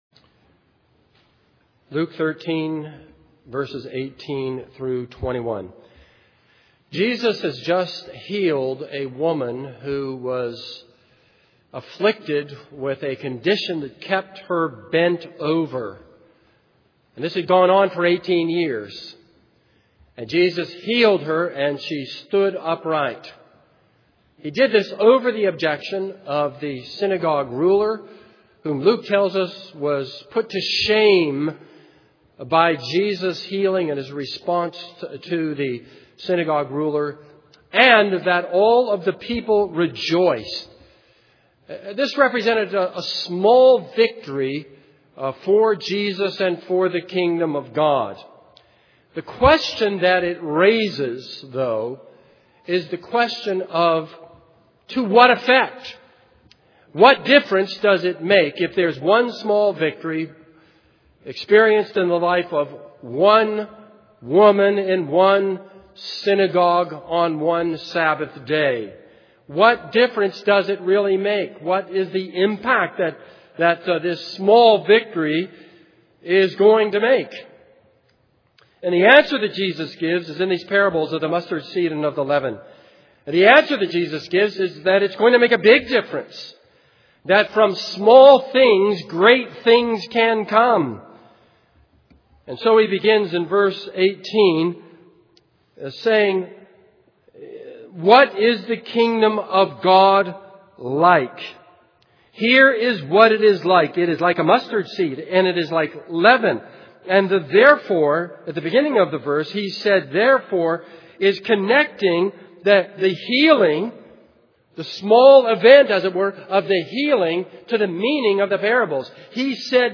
This is a sermon on Luke 13:18-21.